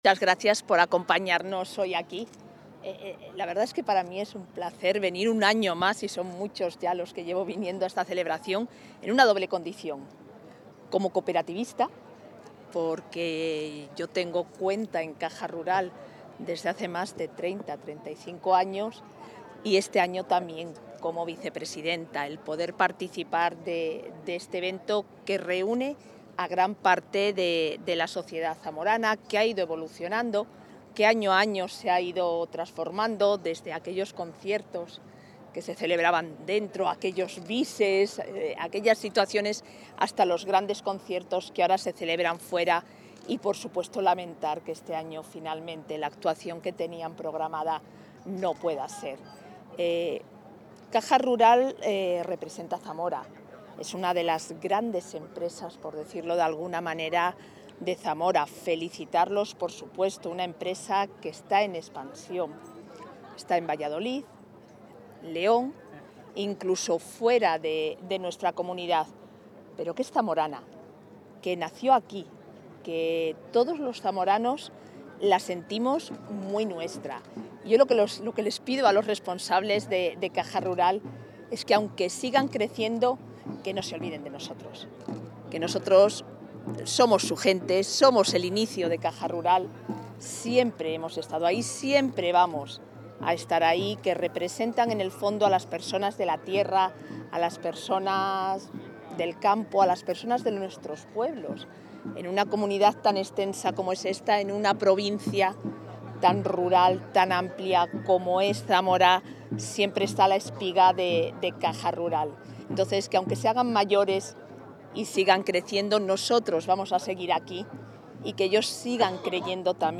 Intervención de la vicepresidenta.
La vicepresidenta de la Junta de Castilla y León y consejera de Familia e Igualdad de Oportunidades, Isabel Blanco, ha participado en la entrega de los XXVIII Premios de la Fundación Caja Rural de Zamora.